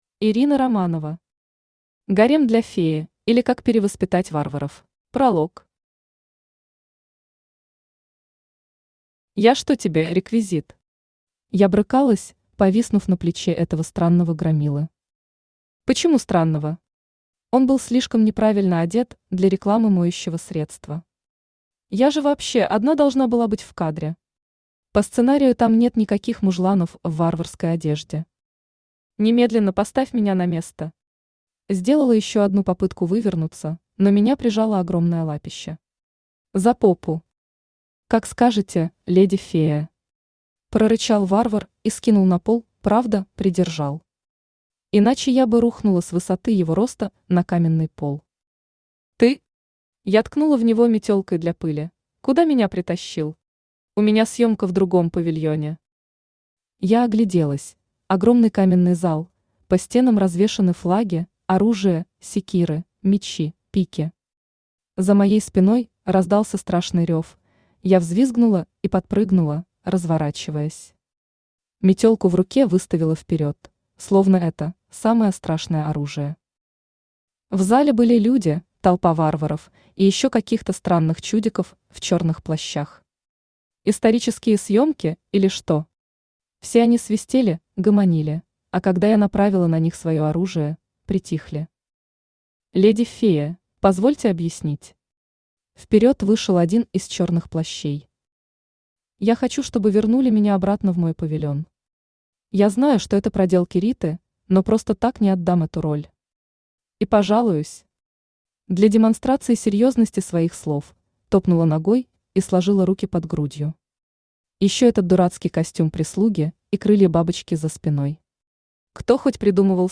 Аудиокнига Гарем для феи, или Как перевоспитать варваров | Библиотека аудиокниг
Aудиокнига Гарем для феи, или Как перевоспитать варваров Автор Ирина Романова Читает аудиокнигу Авточтец ЛитРес.